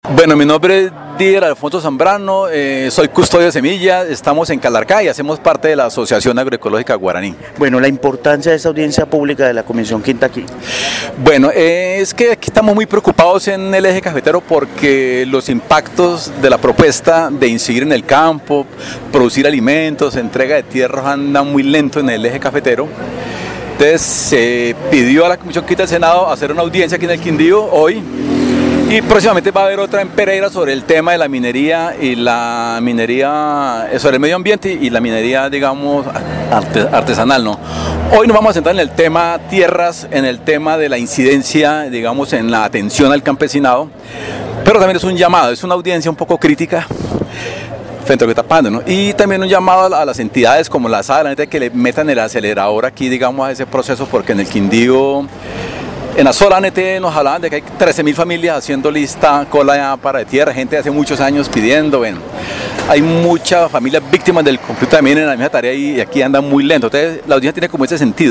Así fue dado a conocer durante audiencia pública que lideró la Comisión Quinta del Senado en Armenia
campesino del Quindío